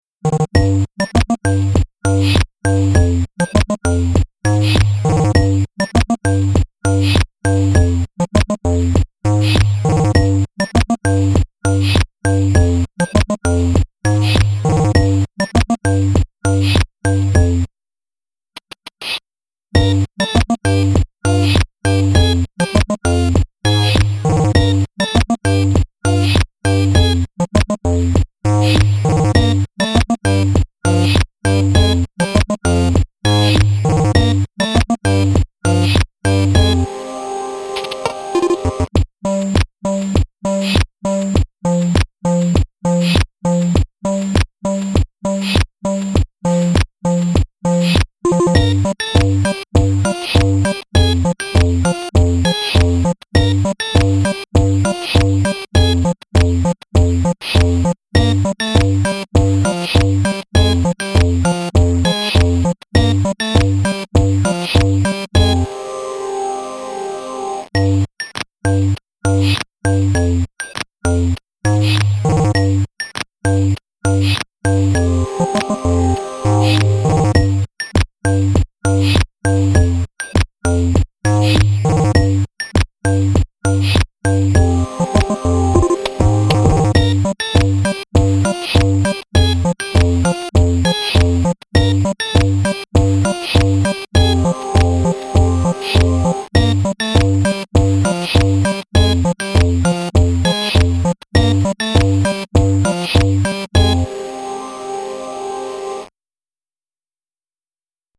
Quirky chopped retro electro.